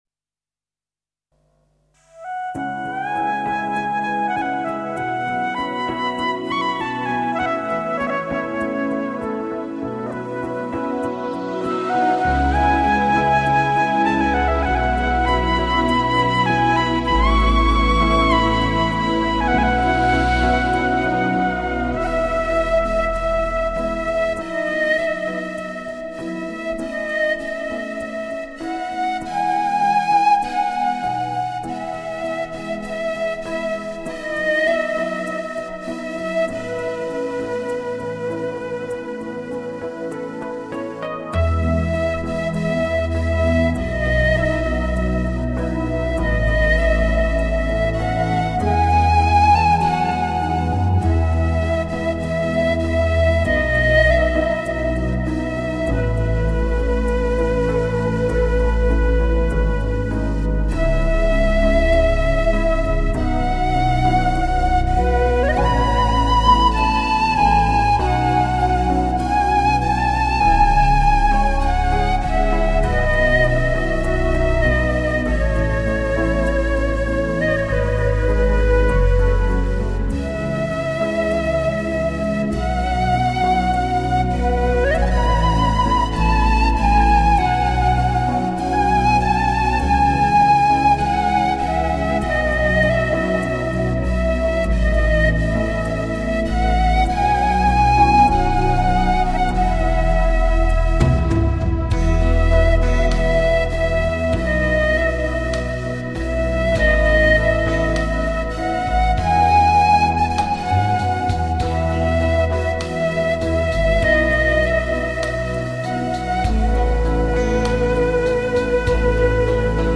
排笛音域宽广，音区变化大，音色丰富。
这样的音 质，适合表现空灵的意境，纷飞的思绪，缥缈的梦境，醇厚的深情。